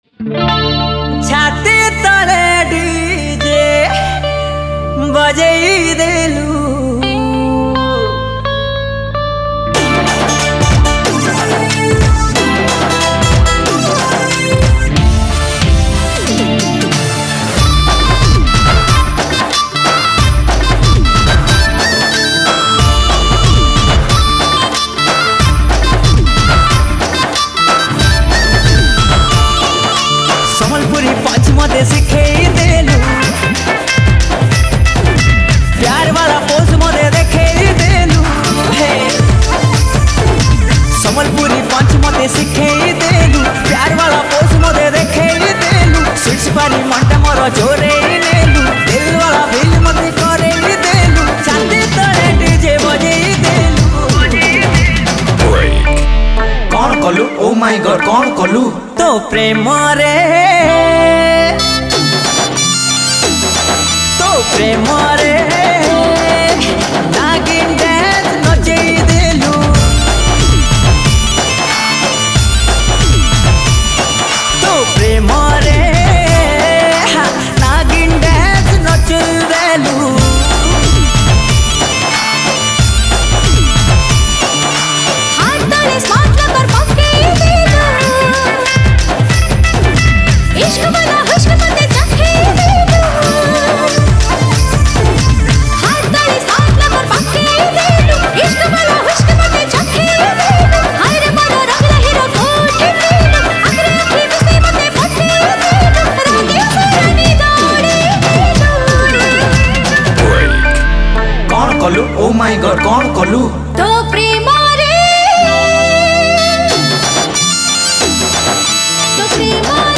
Studio Version